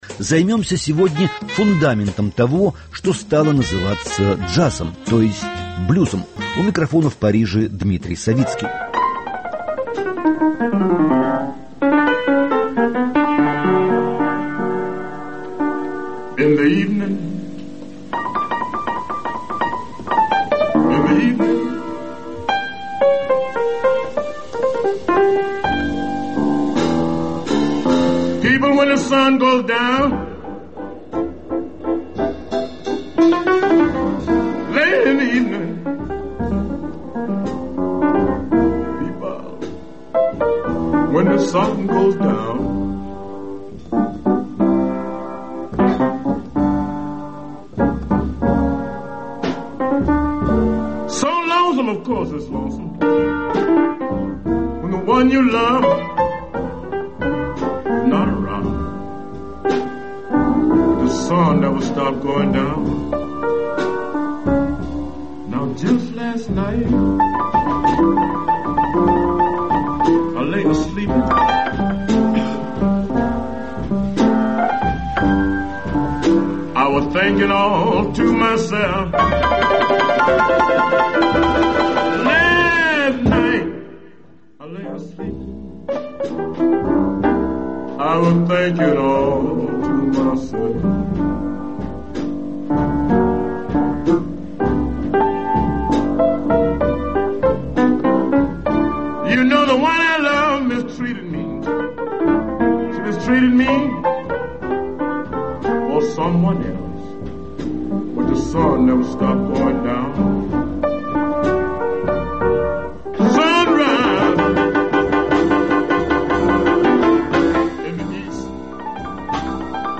Рассказывает Дм.Савицкий